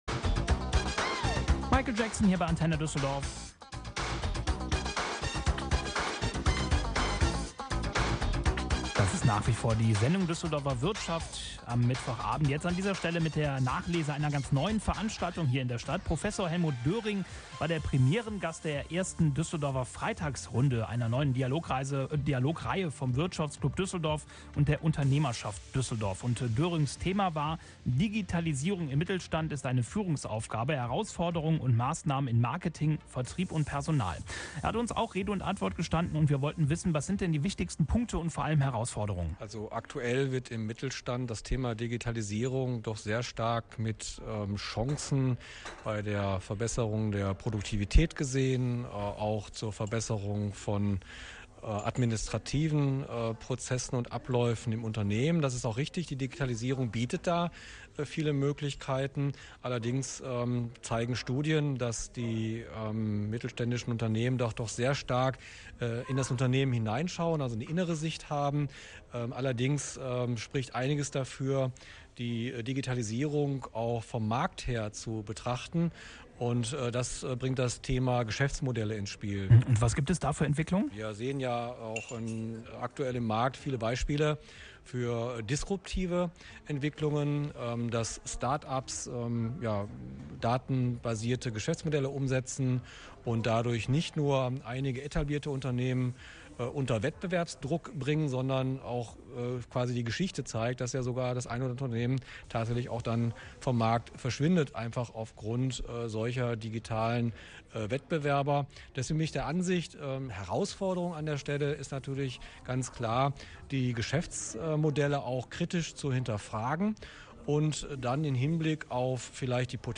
Radiointerview zur Digitalisierung im Mittelstand